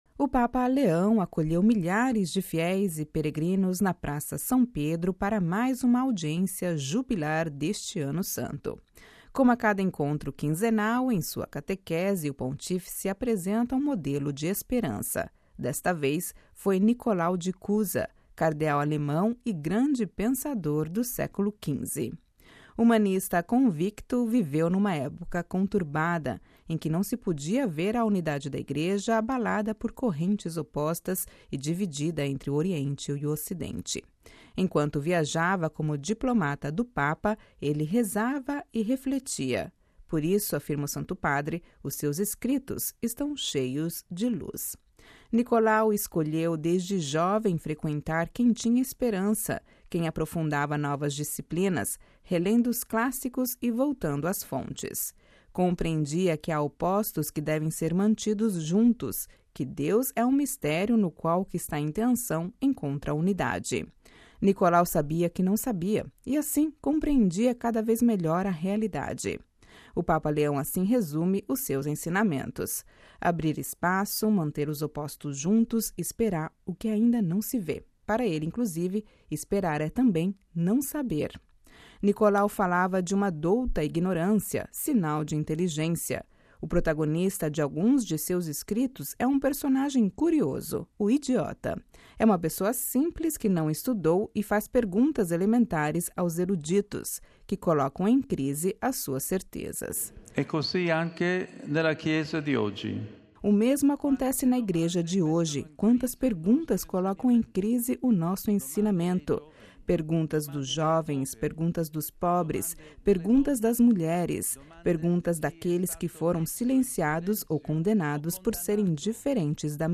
O Papa Leão acolheu milhares de fiéis e peregrinos na Praça São Pedro para mais uma audiência jubilar deste Ano Santo.